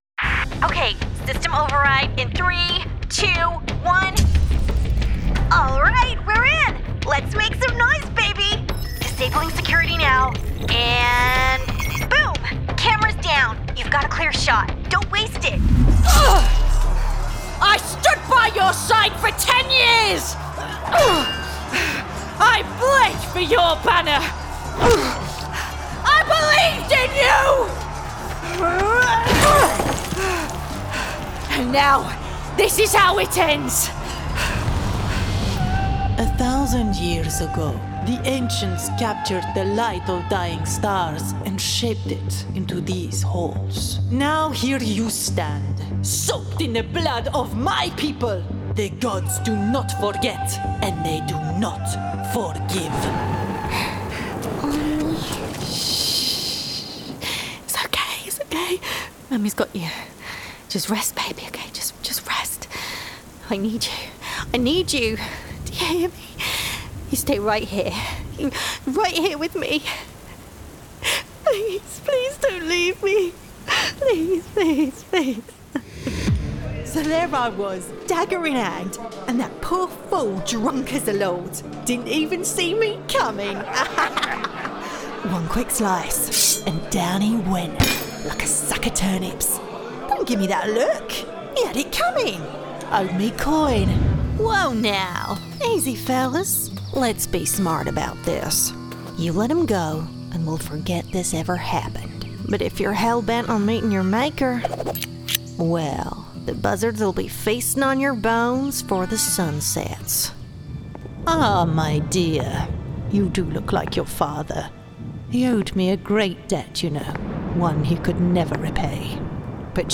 Female
Bright, Friendly, Versatile, Character, Confident, Corporate, Engaging, Natural, Reassuring, Warm
My natural voice is bright, upbeat and friendly with a neutral British accent.
Corporate Showreel.mp3
Microphone: Rode NT1-A
Audio equipment: Audient iD4 interface, treated studio booth